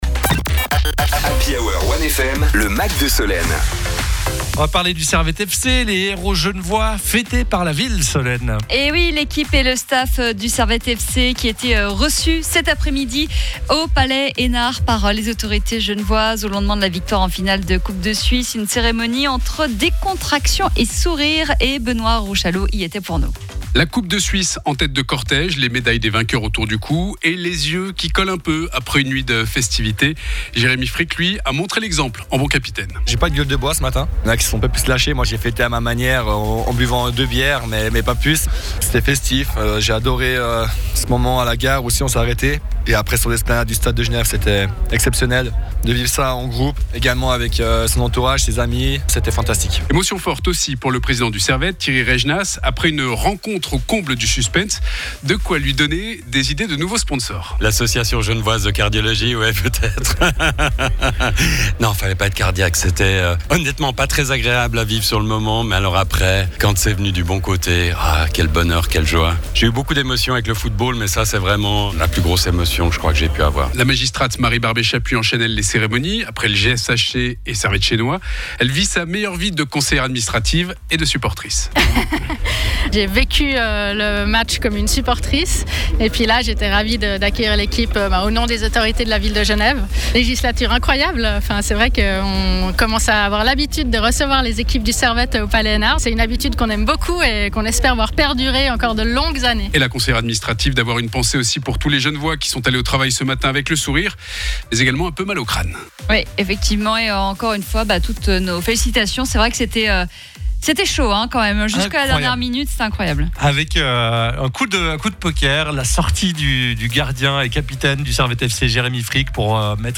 L’équipe et le staff du Servette FC étaient reçus lundi après-midi au Palais Eynard par les autorités genevoises, au lendemain de la victoire en finale de la Coupe de Suisse. Une cérémonie entre décontraction et sourires.